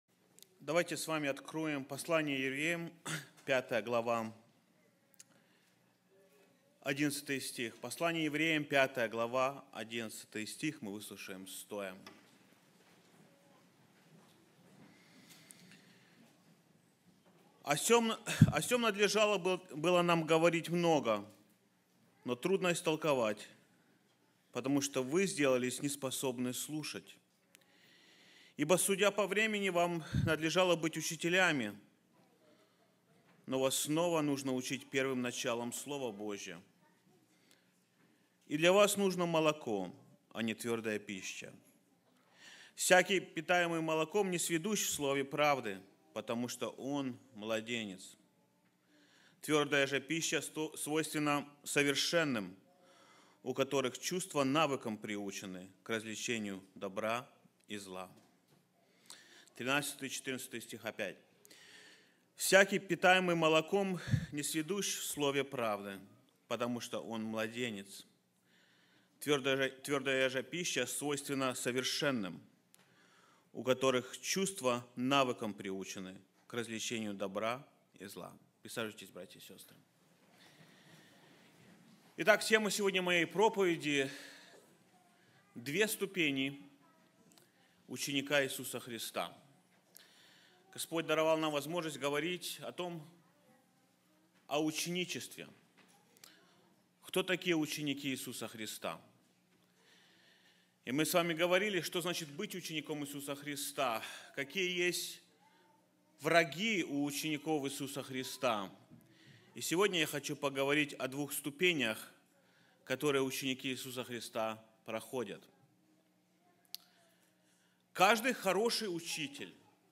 sermon-two-steps-disciple.mp3